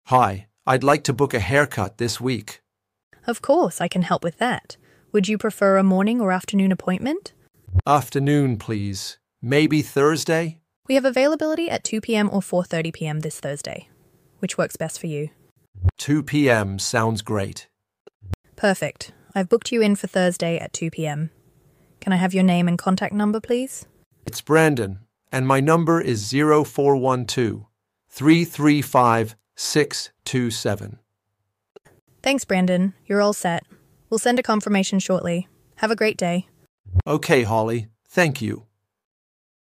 No humans needed. This is what Holly sounds like on your business line.